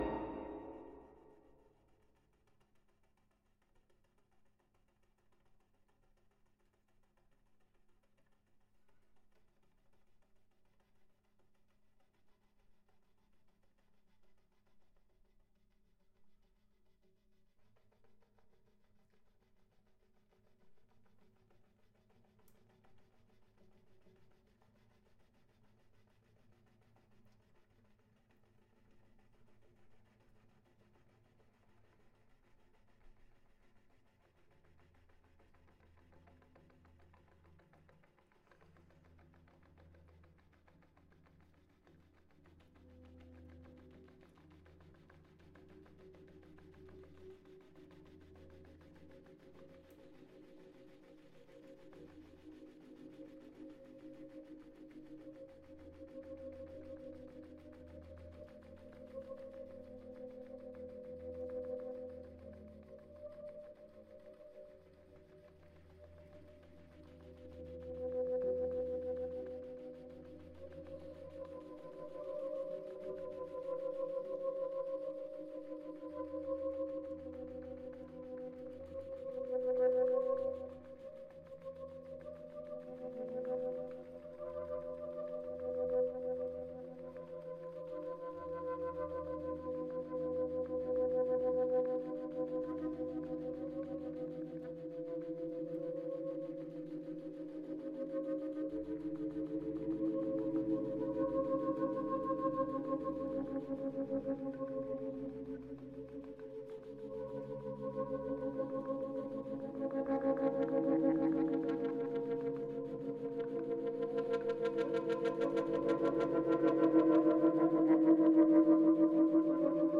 nearly 90-minute long work for saxophone octet